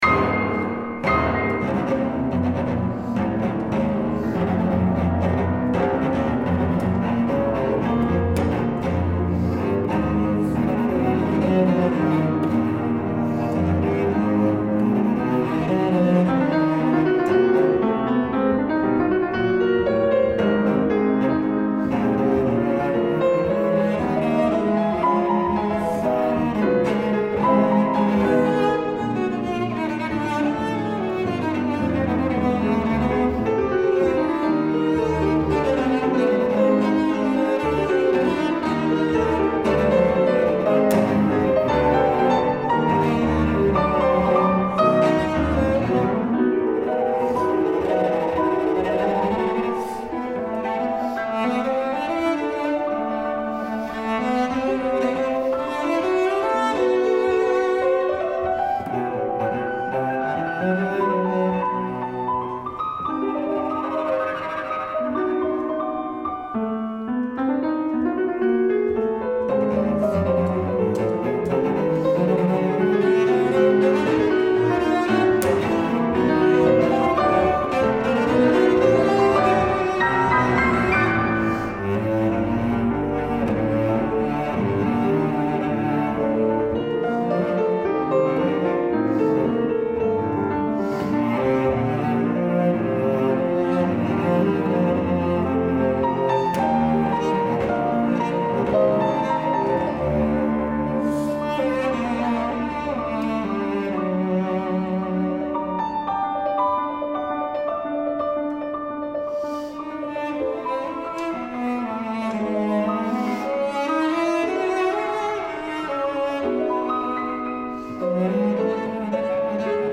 appassionato-for-cello-and-piano-3.mp3